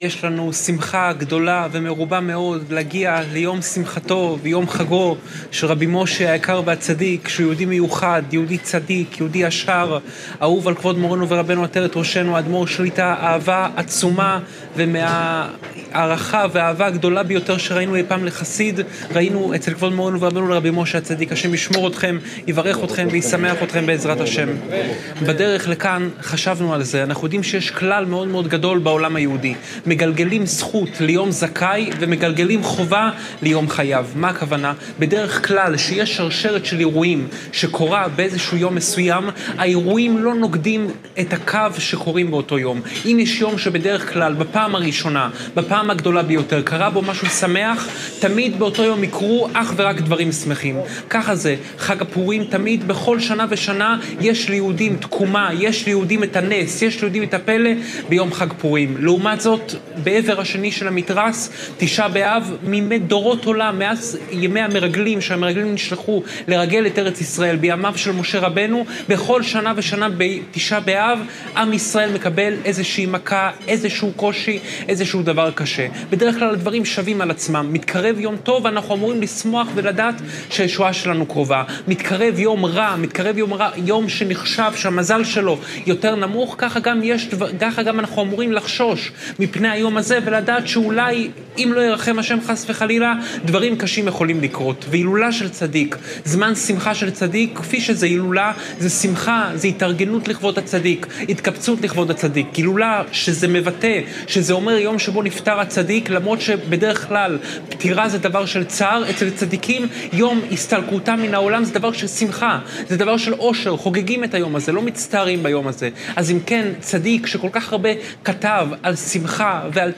שעורי תורה